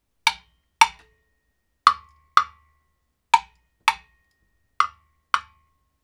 • wooden power blocks and jam blocks studio sample.wav
Recorded in a professional studio with a Tascam DR 40 linear PCM recorder.
wooden_power_blocks_and_jam_blocks_studio_sample_jF9.wav